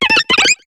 Cri de Ptiravi dans Pokémon HOME.